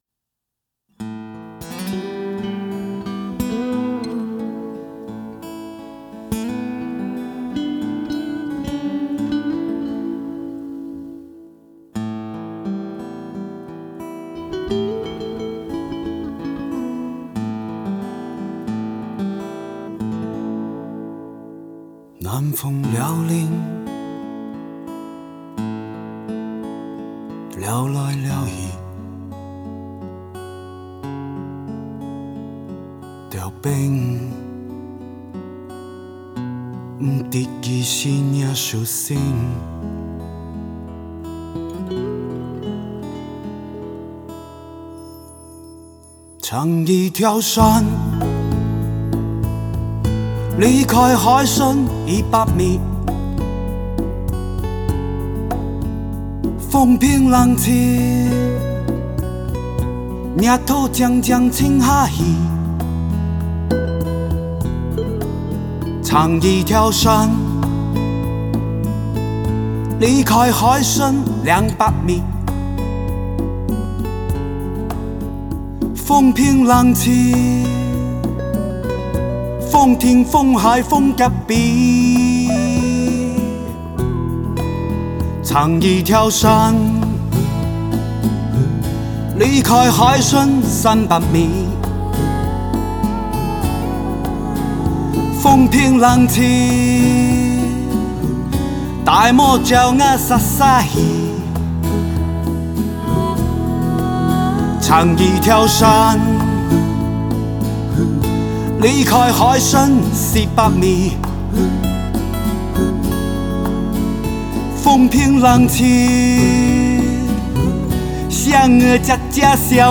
片尾曲
歌曲以柔和的弦乐开篇，纯厚细腻的吉他与质感流畅的贝斯交响合奏，营造出低沉悲戚的氛围
闽南语的唱法更为歌曲增添了许多韵味
压抑的沉唱伴随着男人的哼唱
轻快的鼓点切入，女人的吟唱轻轻响起